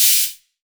Index of /90_sSampleCDs/EdgeSounds - Drum Mashines VOL-1/CZ-DRUMS KIT